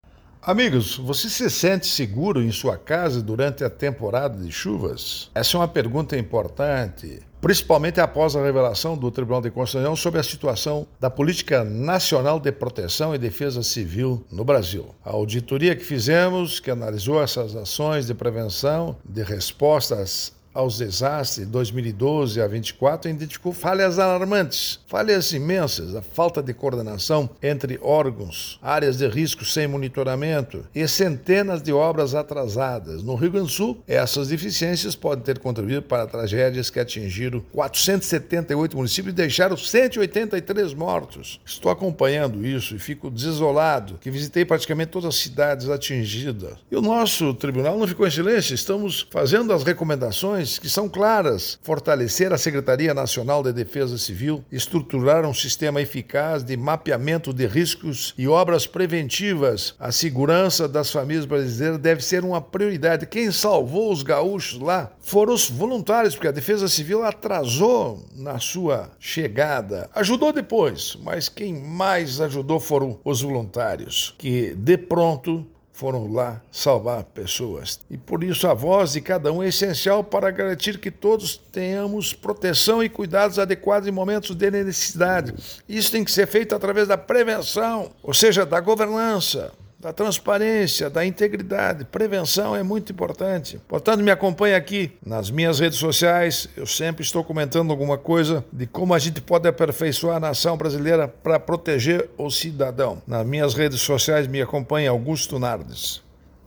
02-Ministro-prog-radio-Defesa-civil-sem-estrutura.mp3